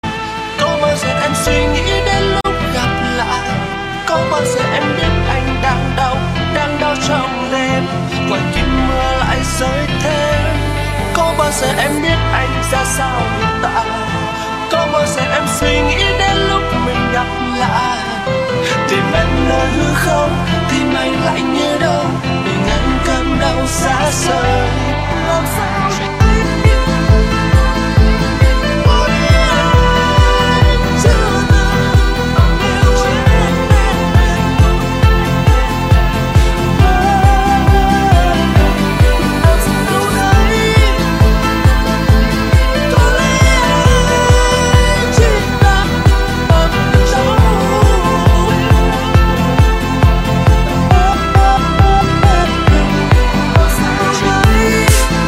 Nhạc Chuông DJ - Nonstop